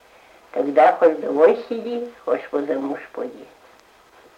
Произнесение слова хочешь как хошь
/то-гда” хо”ш вдо-во”й с’и-д’и” хо”ш во-за-му”ж по-д’и”/